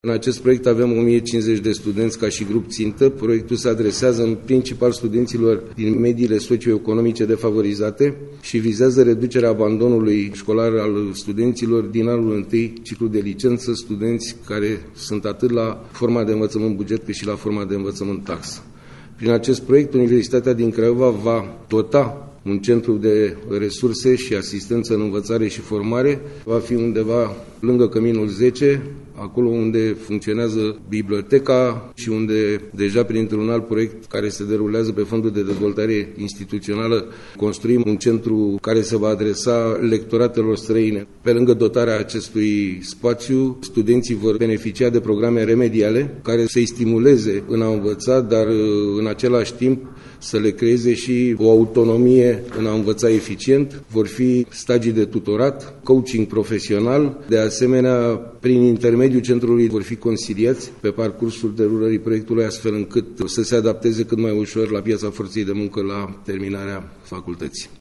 în cadrul unei conferințe de presă